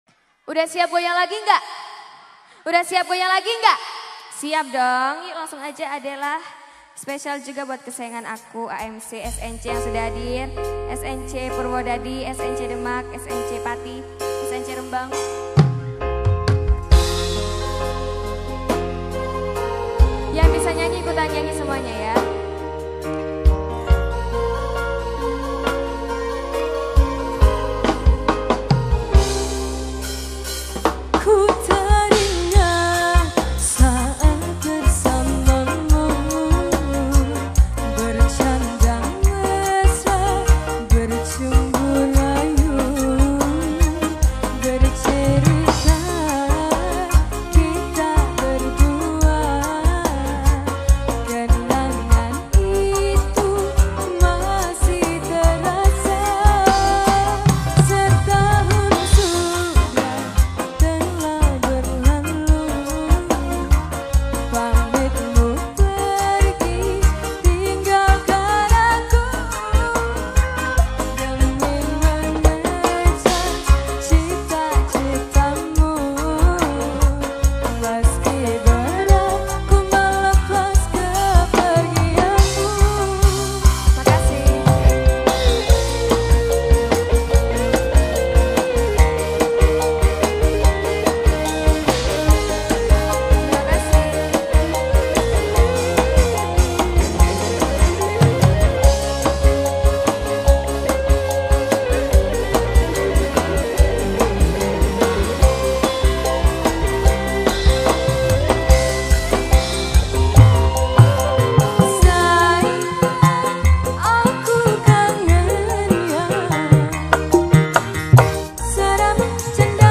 Dangdut Koplo